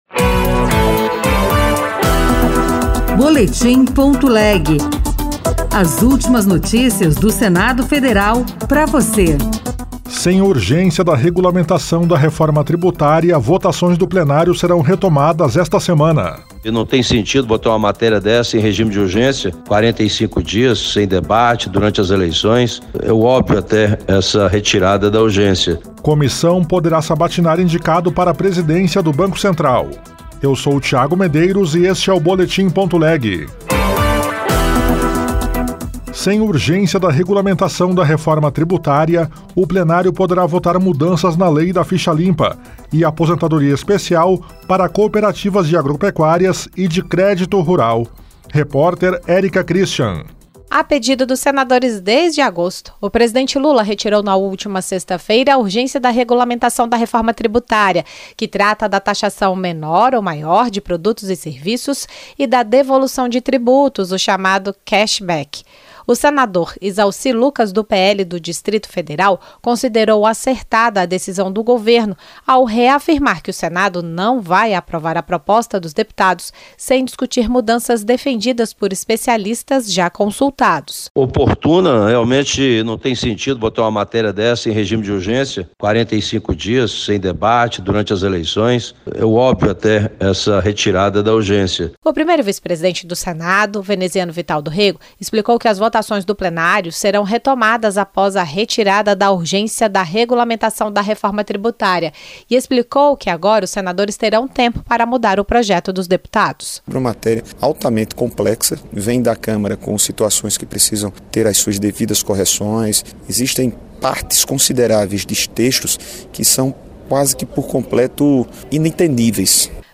Boletim.leg - Edição das 14h — Rádio Senado